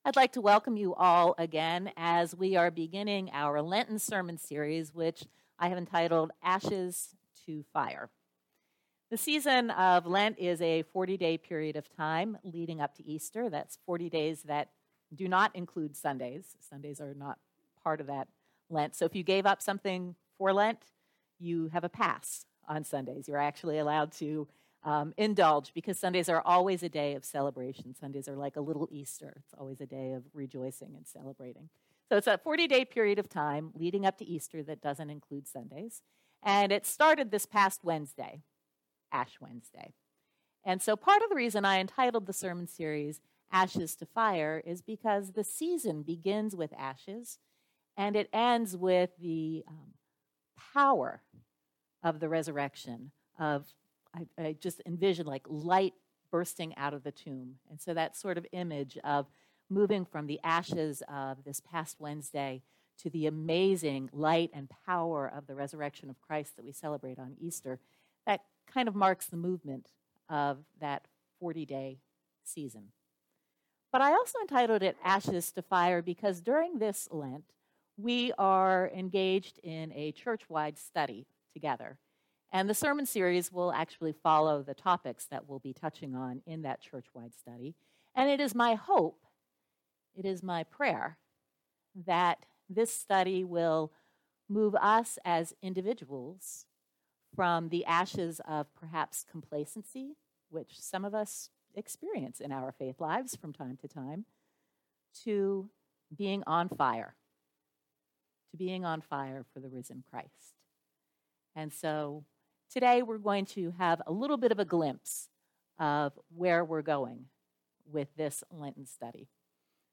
Morrisville United Methodist Church Sermons